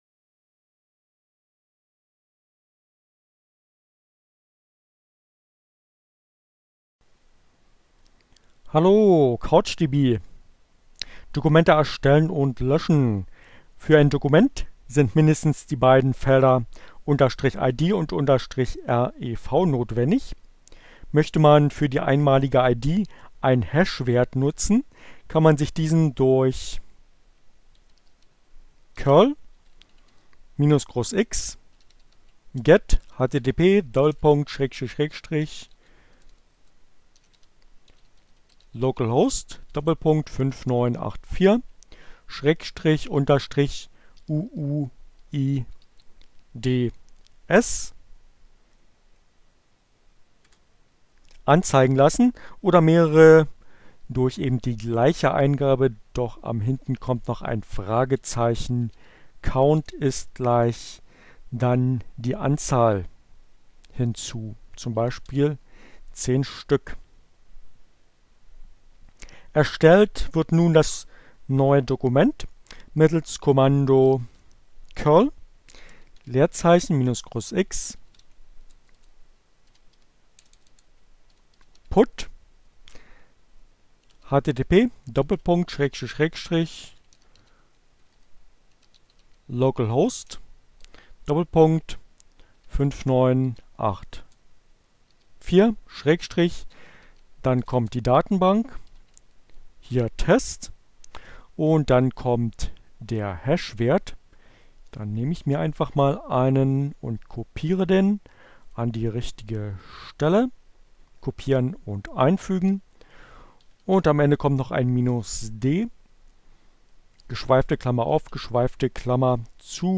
Tags: Fedora, Gnome, Linux, Neueinsteiger, Ogg Theora, ohne Musik, screencast, CC by, Gnome3, couchdb, NoSQL, Datenbank